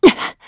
Isolet-Vox_Landing_kr.wav